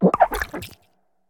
Cri d'Olivini dans Pokémon HOME.